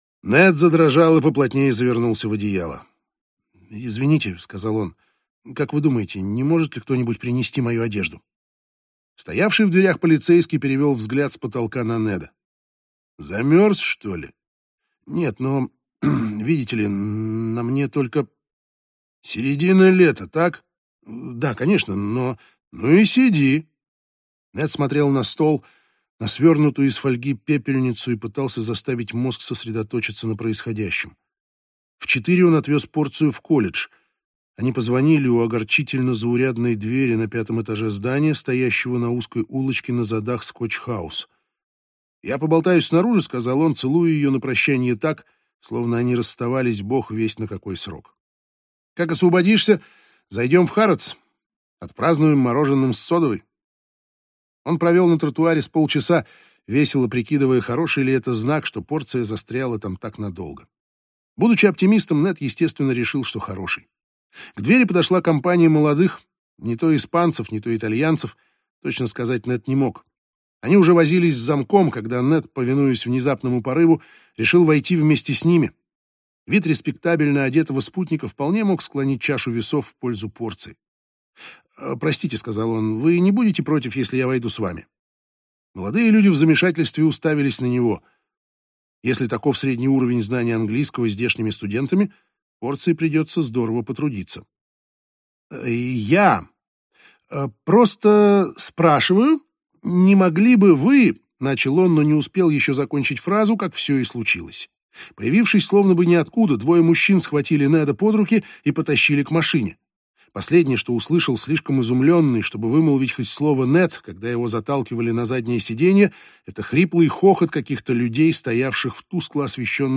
Аудиокнига Теннисные мячики небес | Библиотека аудиокниг
Прослушать и бесплатно скачать фрагмент аудиокниги